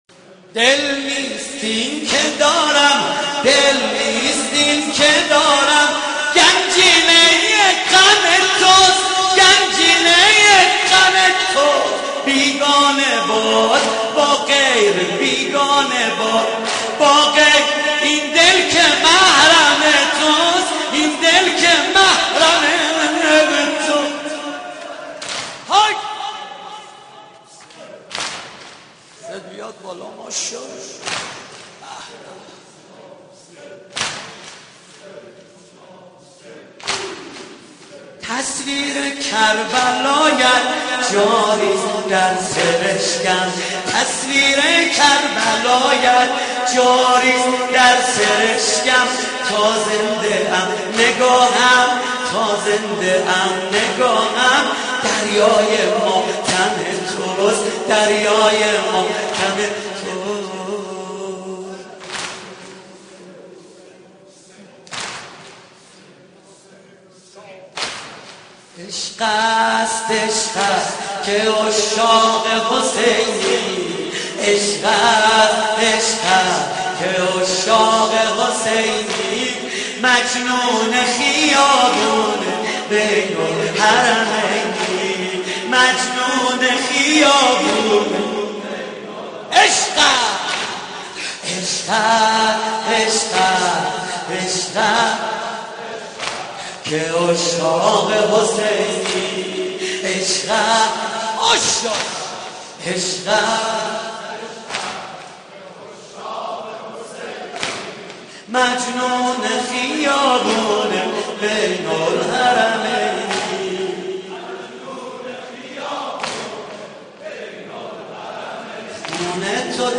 محرم 88 - سینه زنی 6
محرم-88---سینه-زنی-6